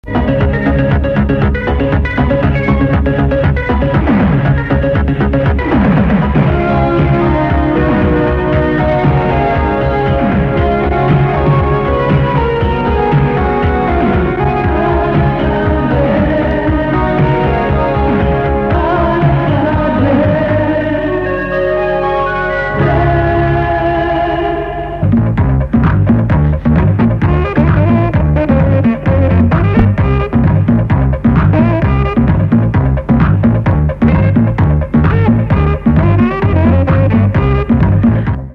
Sintonia del programa amb identificació cantada